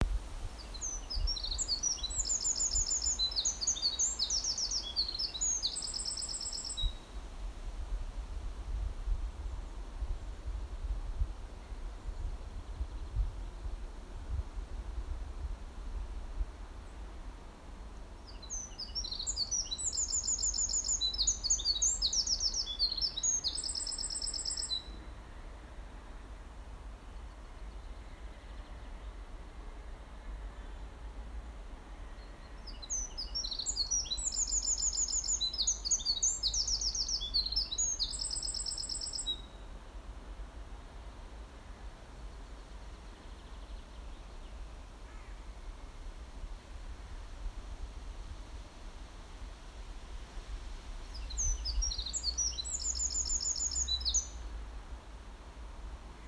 A wren | Gärdsmyg
Here is a wren again. There seems to be a lot of them this summer...